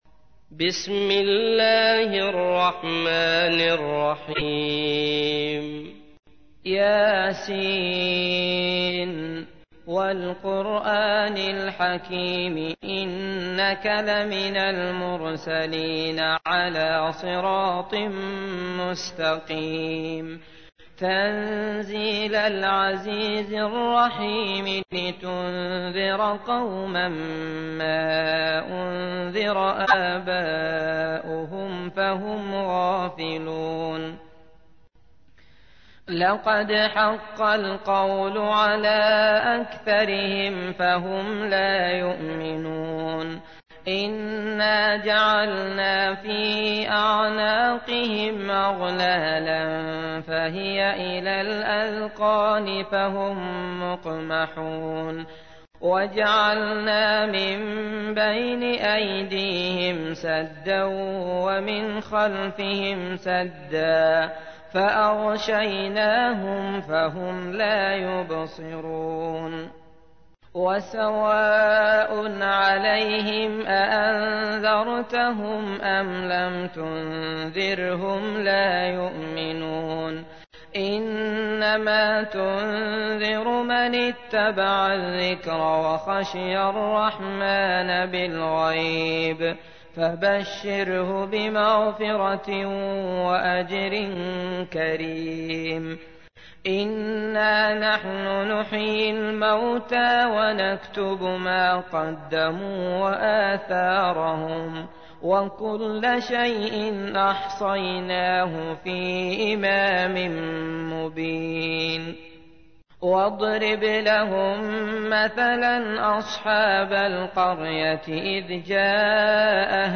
تحميل : 36. سورة يس / القارئ عبد الله المطرود / القرآن الكريم / موقع يا حسين